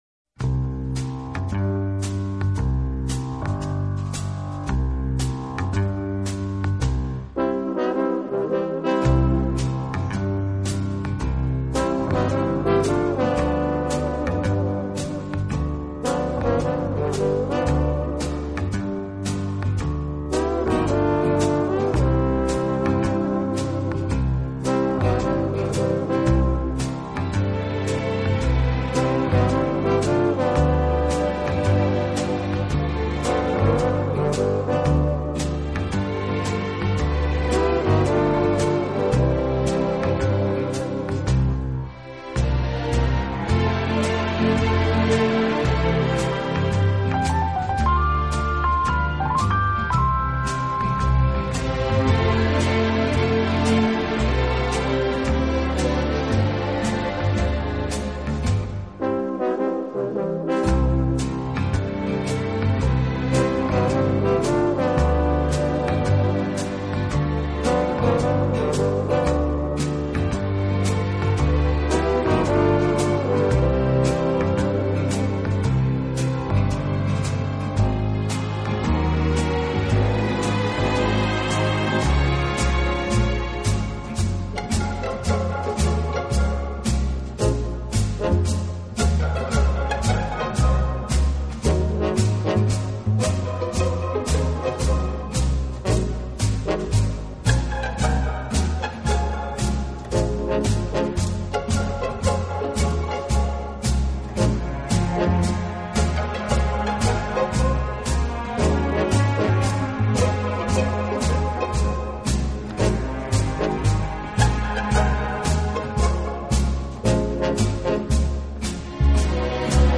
【顶级轻音乐】